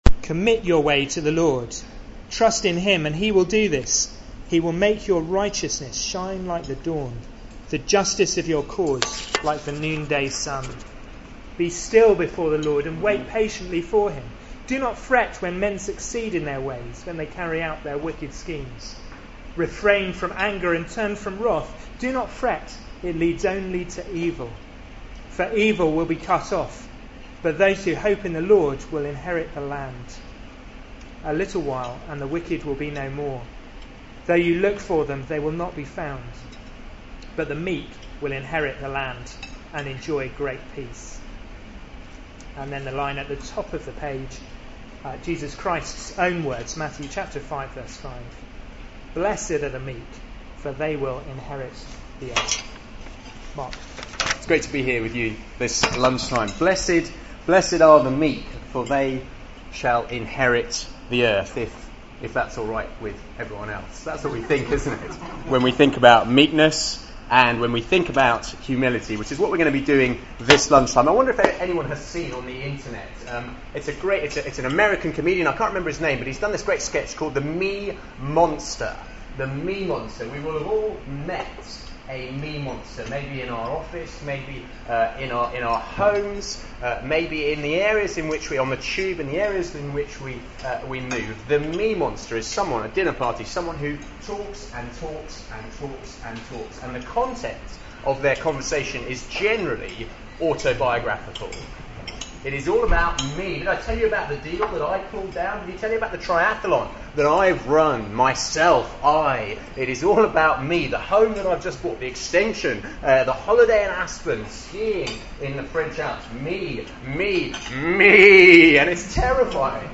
given at a Wednesday meeting